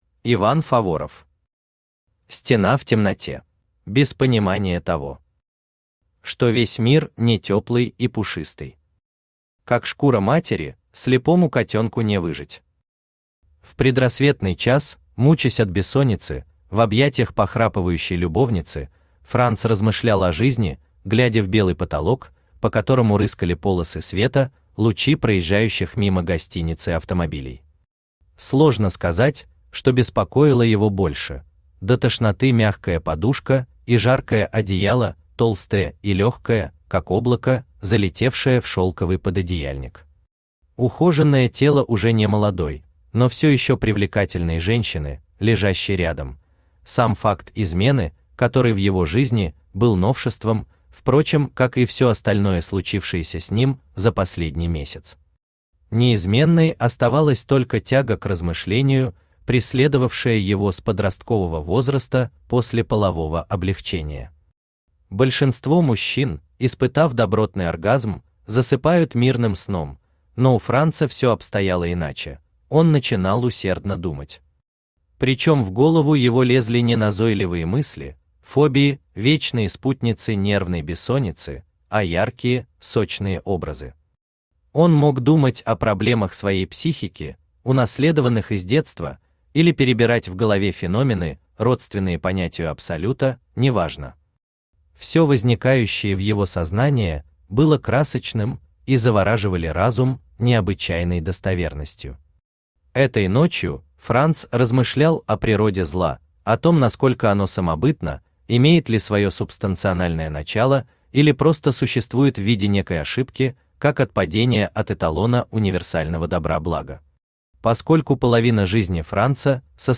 Аудиокнига Стена в темноте | Библиотека аудиокниг
Aудиокнига Стена в темноте Автор Иван Геннадьевич Фаворов Читает аудиокнигу Авточтец ЛитРес.